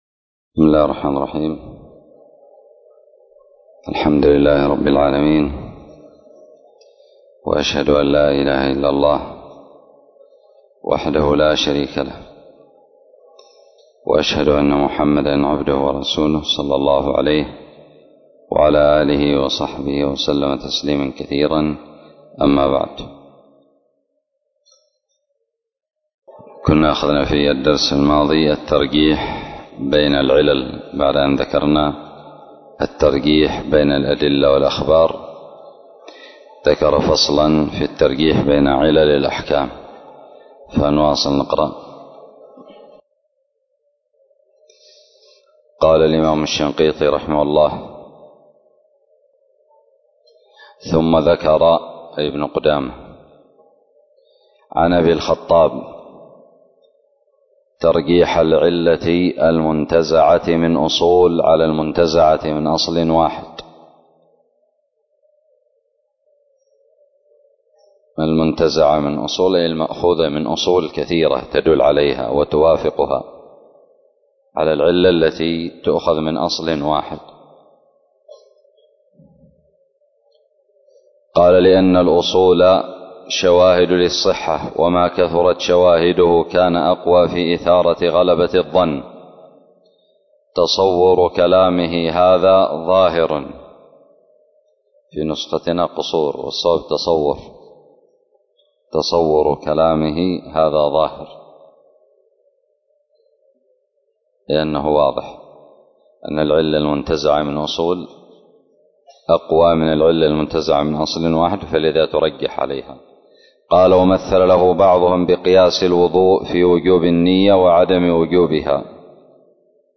الدرس السابع عشر بعد المائة والأخير من شرح مذكرة أصول الفقه
ألقيت بدار الحديث السلفية للعلوم الشرعية بالضالع